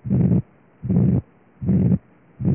B2 com sopro, protomesosistólico, amplo, de ejeção, moderado, na área pulmonar de grau I-III por VI.